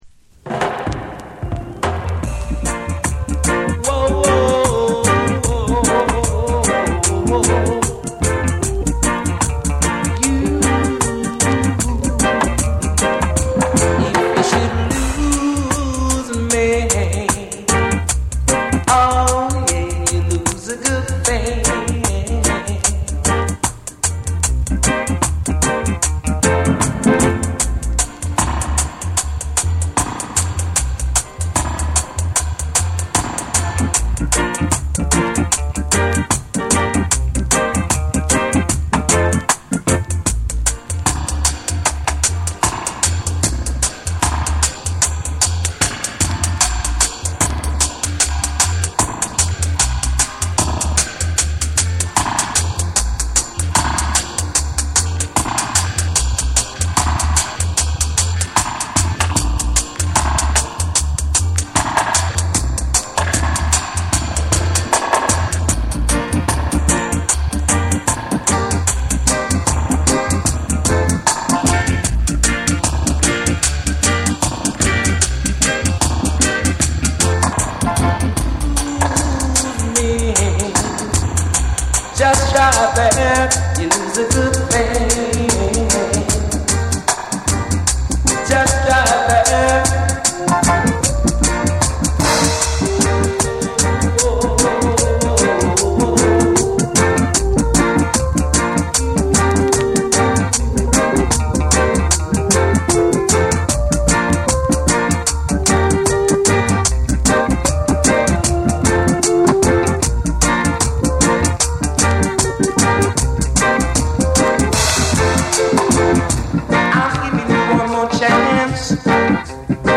鋭いベースと宇宙的エコーが全編を貫き